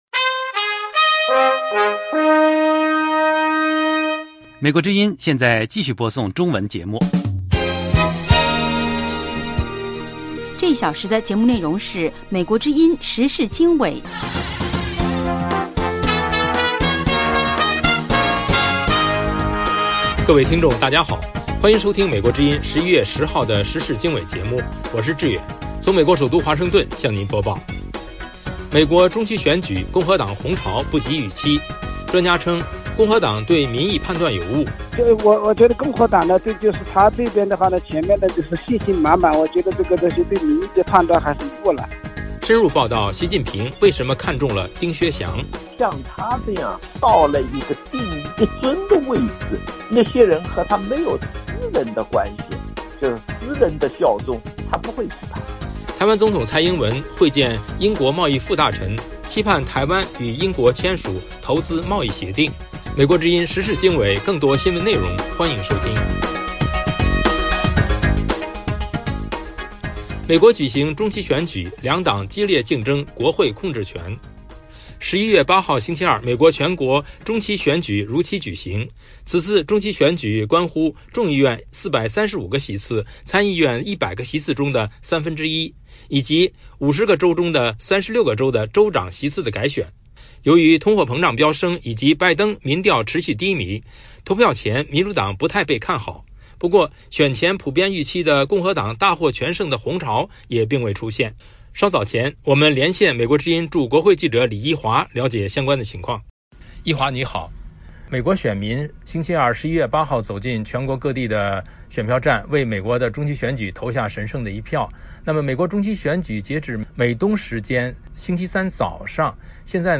记者连线
2/深入报道：习近平为什么看中了丁薛祥？